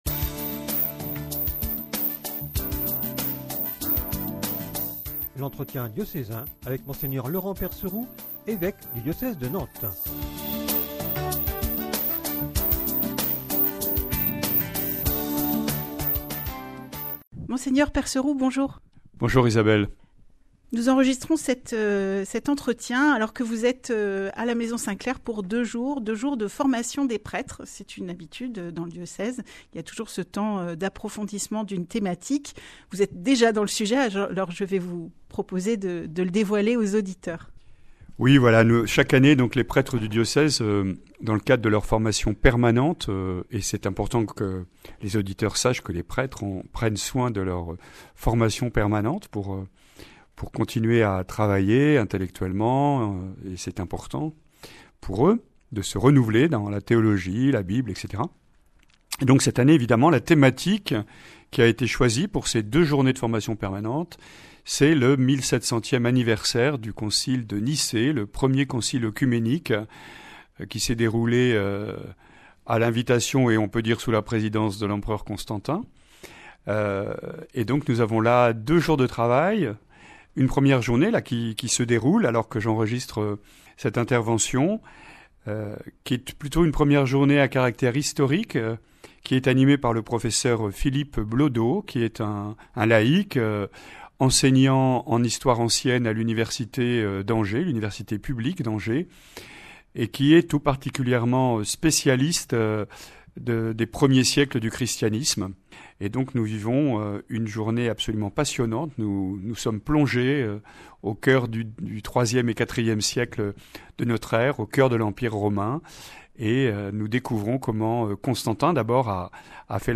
Entretien diocésain avec Mgr Percerou | Diocèse de Nantes
Dans l’entretien à Radio Fidélité du 5 avril 2024, Mgr Percerou évoque tour à tour :